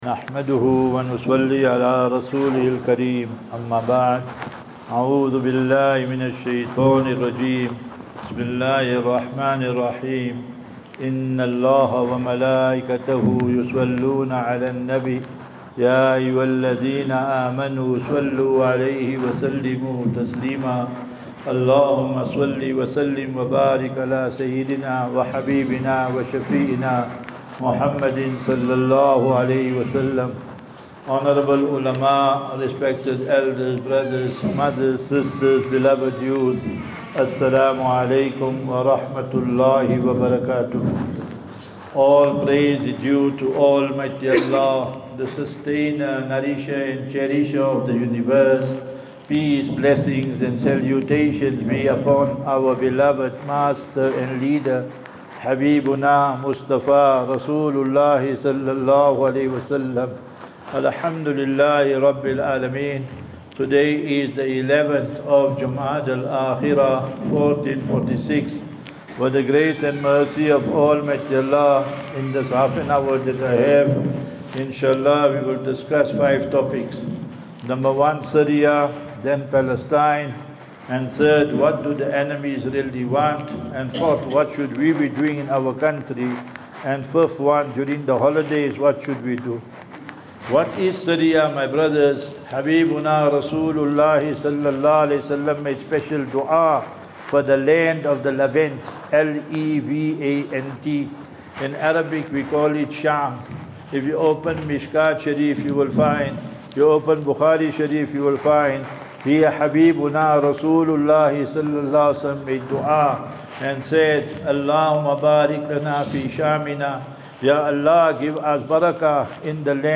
13 Dec 13 December 2024 - Balfour Jumma Lecture - What is Syria?